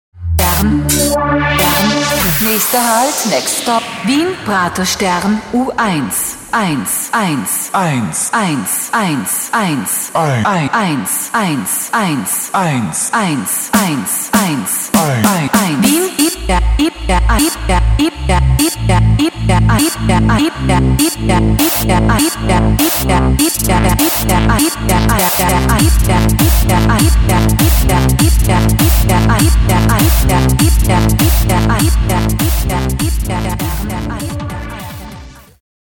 die originale Ansage der S-Bahn in Wien